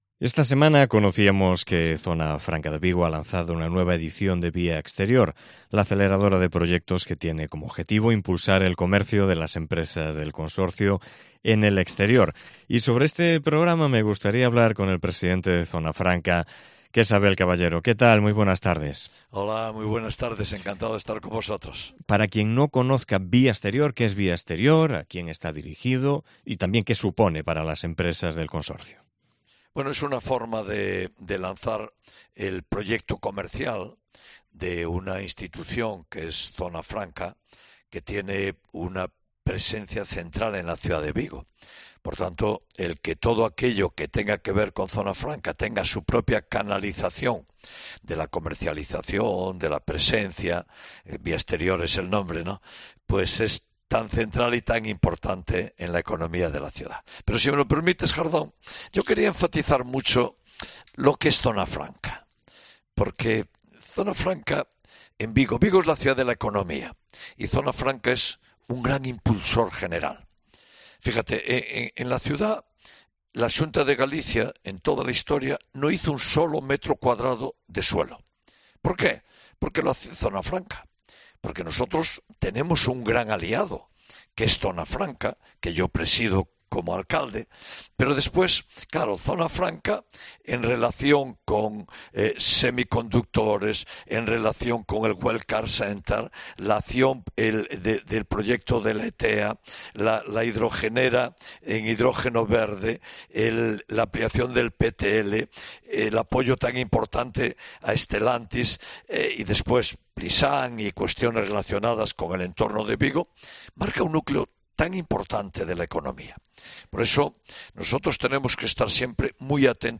Entrevista con Abel Caballero, presidente de Zona Franca de Vigo